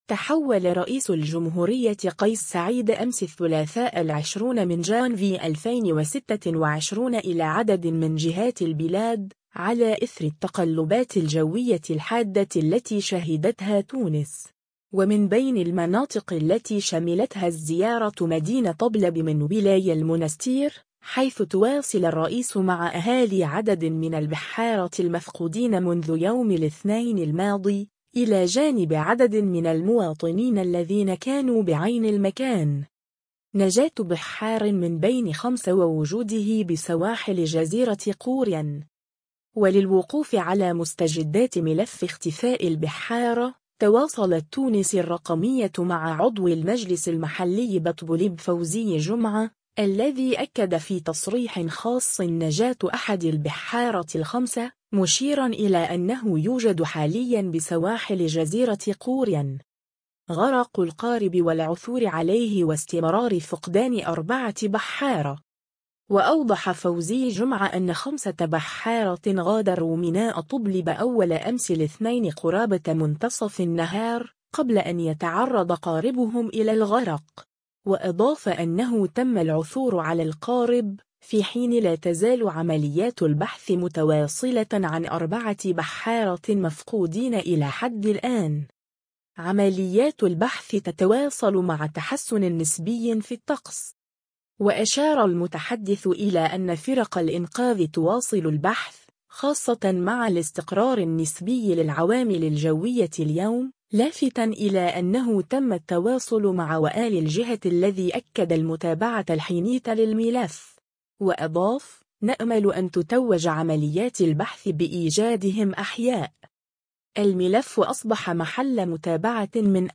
وللوقوف على مستجدات ملف اختفاء البحّارة، تواصلت “تونس الرقمية” مع عضو المجلس المحلي بطبلبة فوزي جمعة، الذي أكد في تصريح خاص نجاة أحد البحّارة الخمسة، مشيرًا إلى أنه يوجد حاليًا بسواحل جزيرة قوريا.